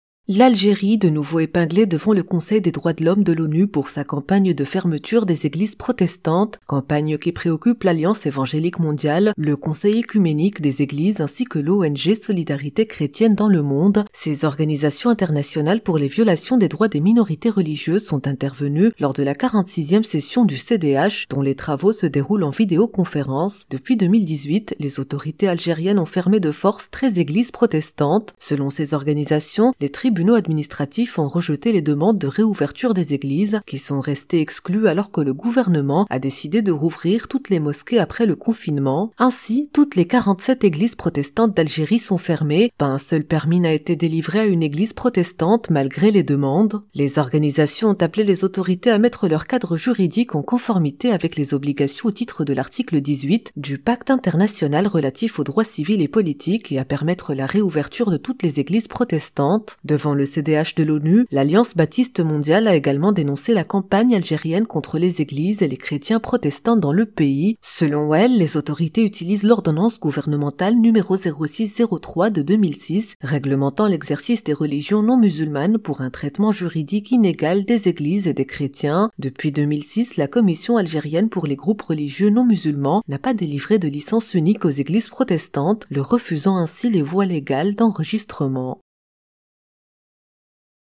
Article à écouter en podcast